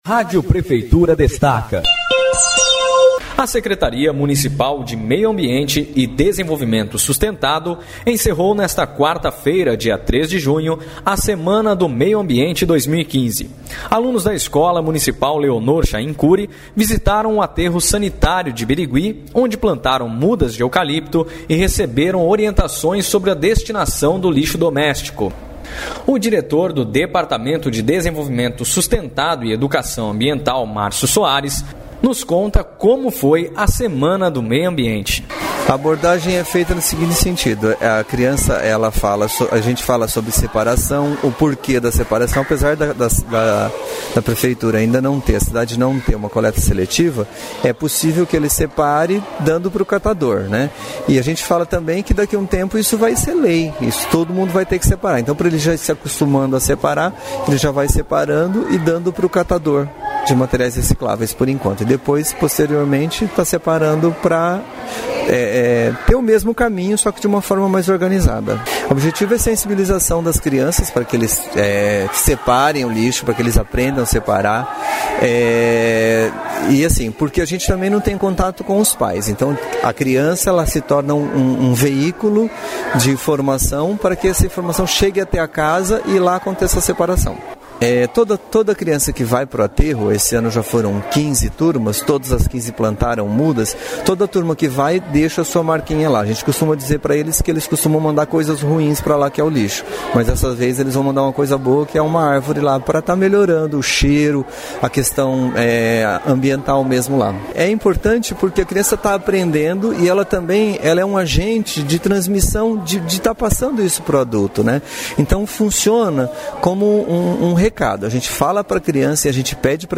Sonora: